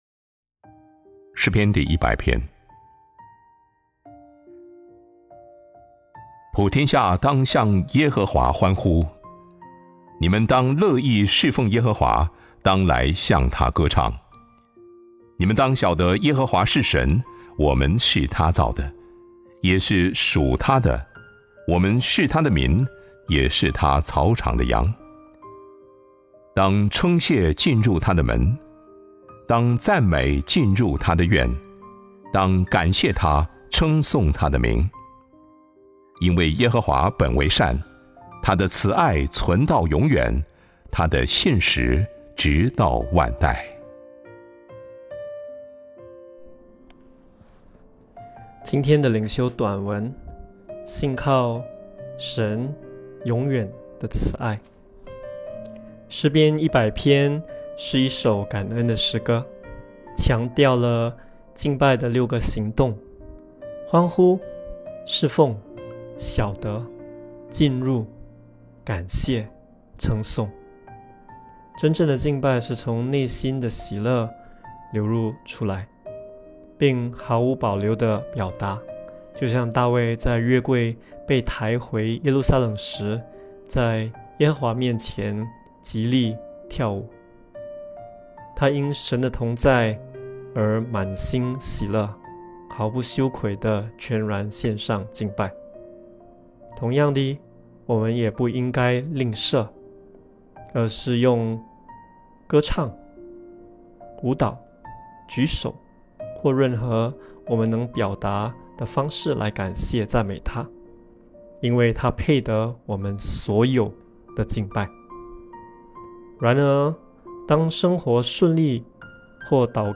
灵修分享